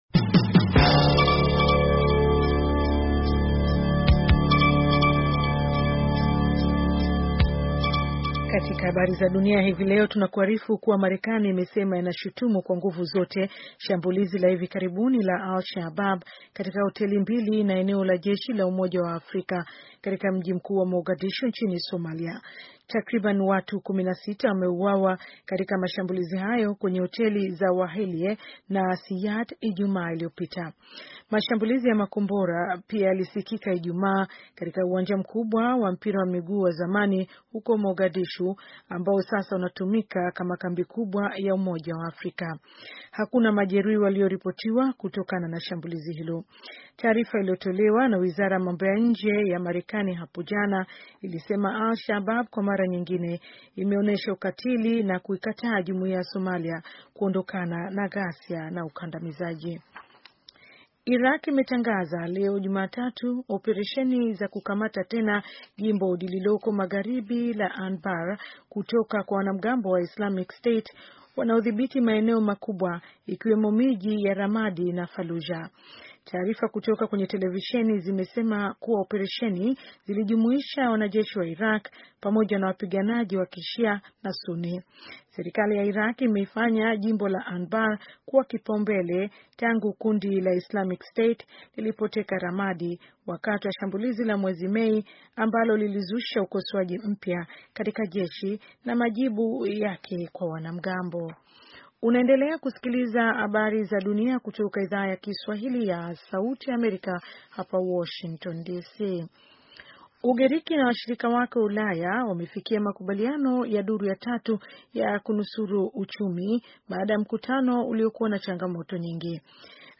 Taarifa ya habari - 4:20